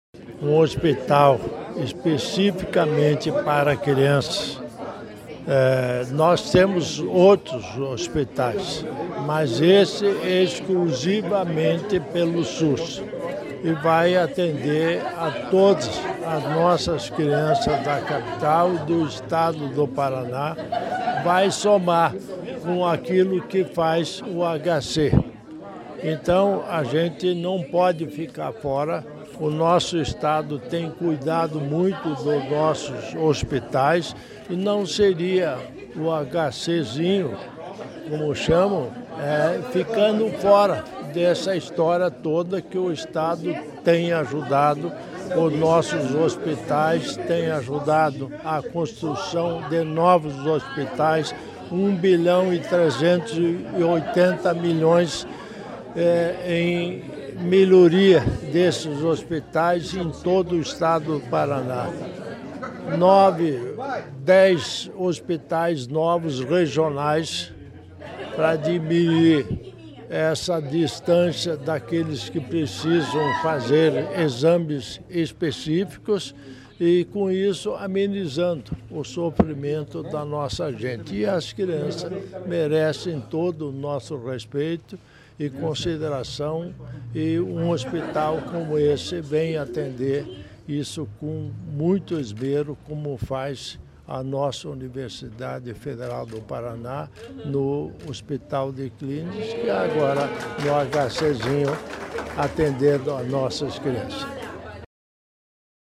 Sonora do vice-governador Darci Piana sobre a entrega de R$ 20 milhões para a construção do Instituto Pediátrico do Hospital de Clínicas